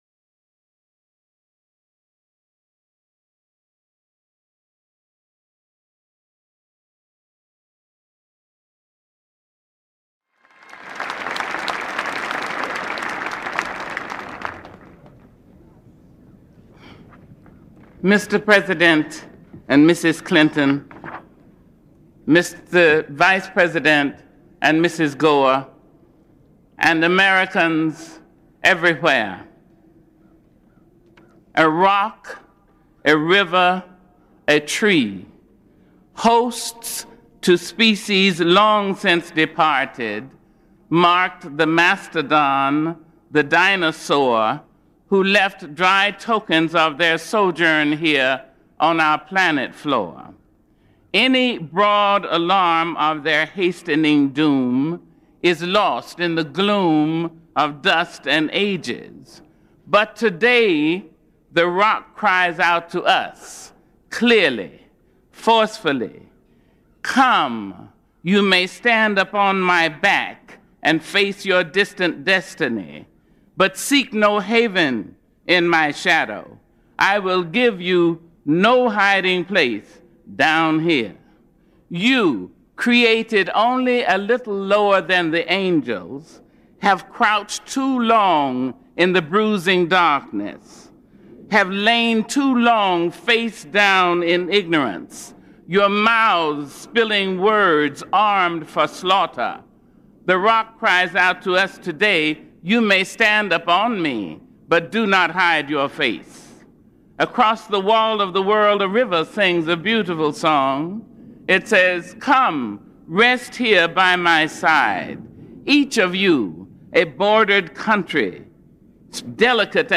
Maya Angelou recites her poem "On the Pulse of Morning" at the 1993 Presidential Inauguration of W.J.Clinton as President on Jan 20, 1993